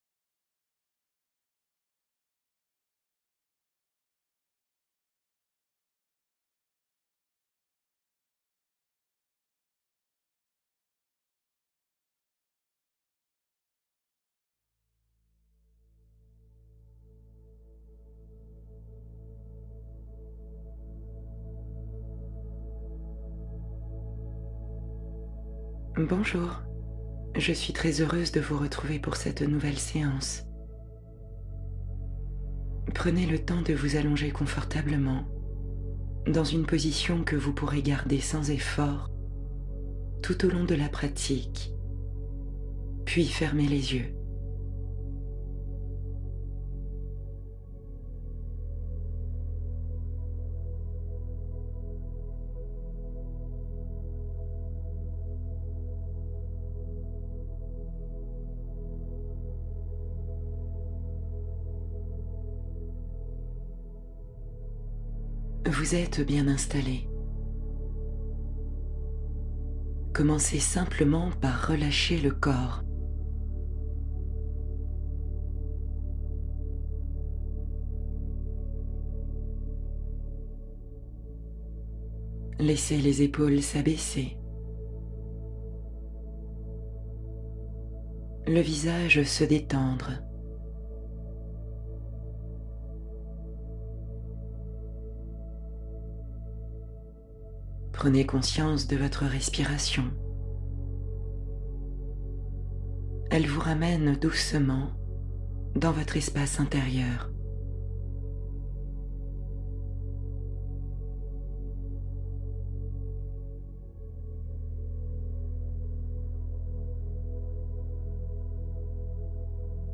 Pause réconfortante profonde — Une méditation pour nourrir l’âme